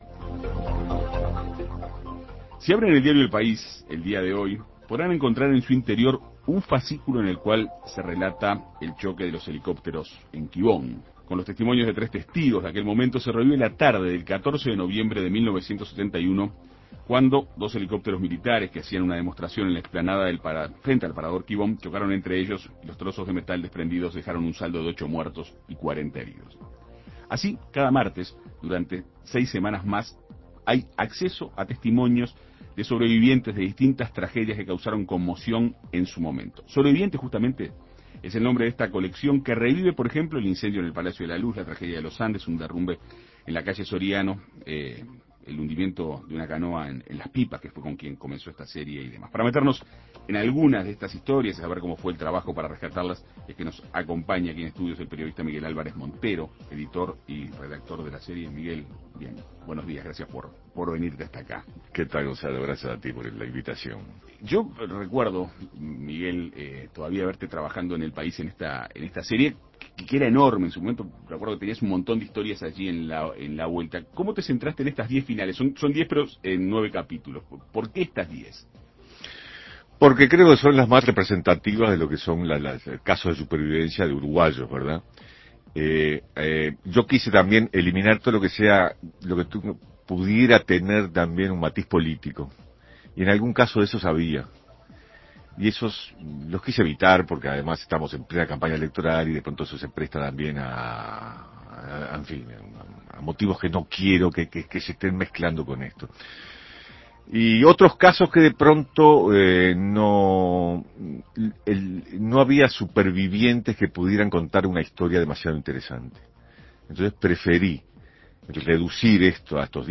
Para conocer de cerca estas historias, En Perspectiva Segunda Mañana dialogó con el periodista